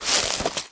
STEPS Bush, Walk 21.wav